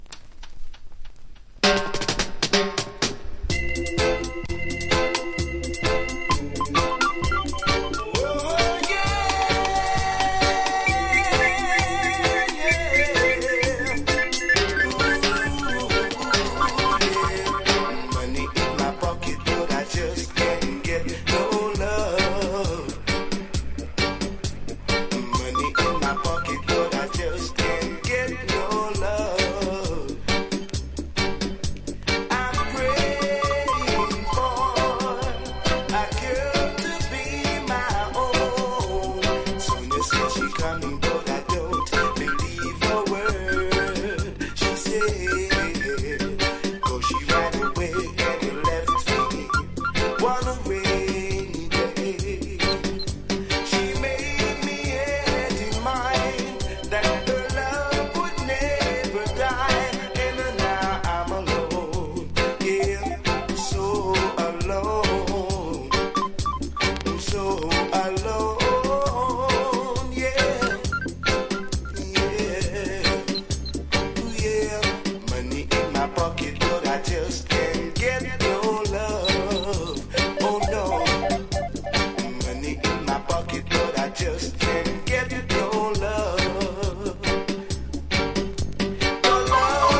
1. REGGAE >
程よくピコピコ・キラキラしたアップデート・ヴァージョンでこちらのほうが好きなリスナーも多いはず!!